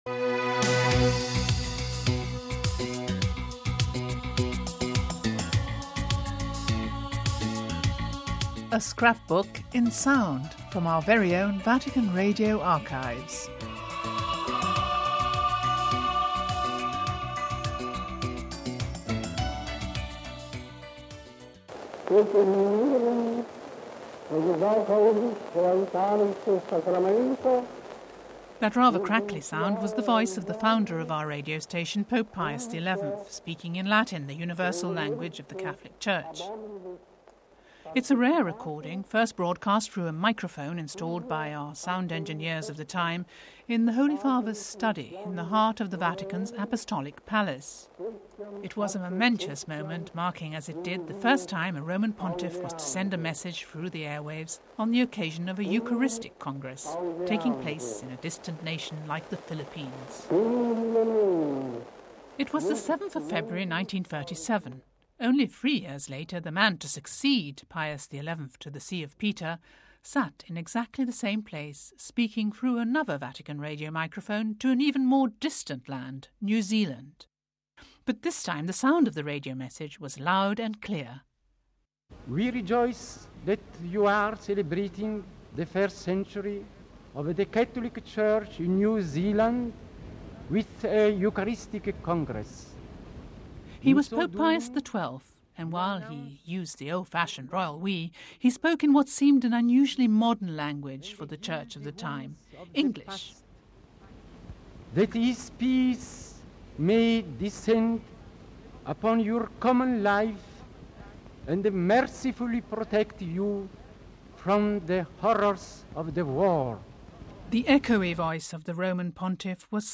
Home Archivio 2006-06-17 10:28:06 GOD GIVEN WAVES Pius XII's radio message in English hurtled over the air waves to New Zealand on the occasion of an International Eucharistic Congress...